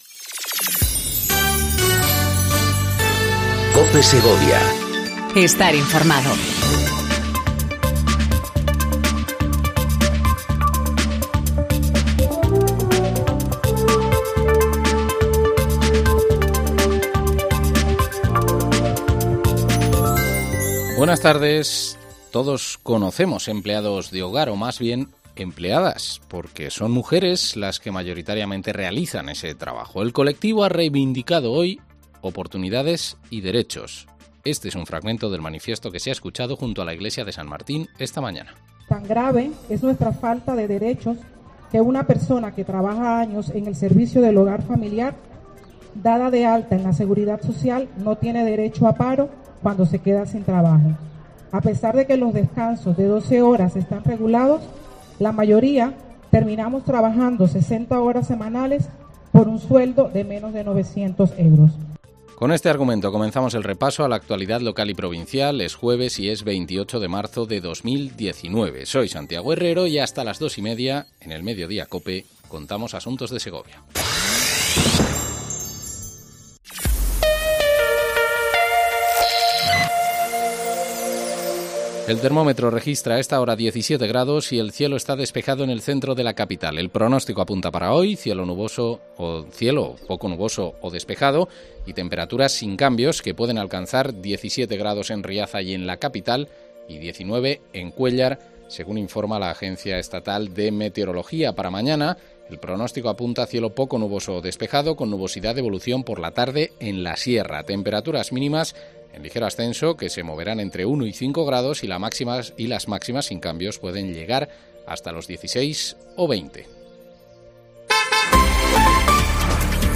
INFORMATIVO DEL MEDIODÍA EN COPE SEGOVIA 14:20 DEL 28/03/19